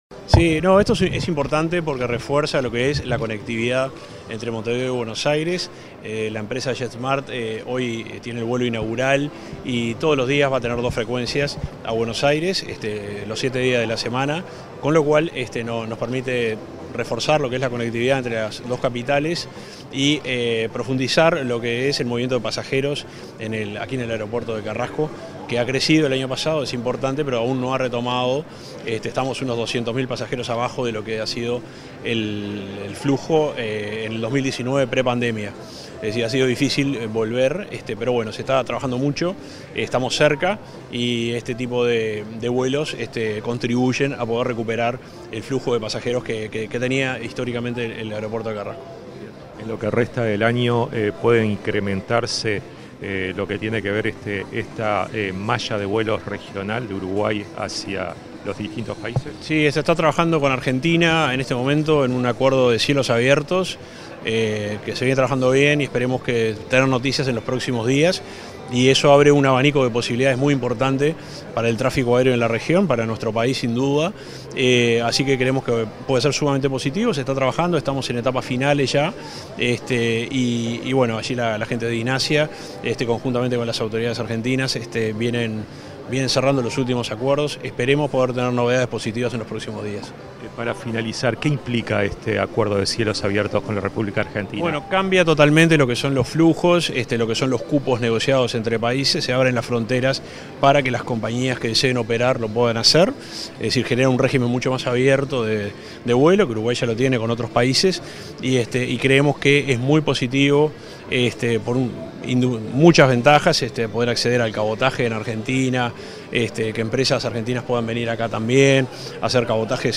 Declaraciones del subsecretario de Transporte y Obras Públicas, Juan José Olaizola
Declaraciones del subsecretario de Transporte y Obras Públicas, Juan José Olaizola 29/05/2024 Compartir Facebook X Copiar enlace WhatsApp LinkedIn Tras el primer día de operación del vuelo Montevideo-Buenos Aires de la compañía Jetsmart, el subsecretario de Transporte y Obras Públicas, Juan José Olaizola, realizó declaraciones a la prensa.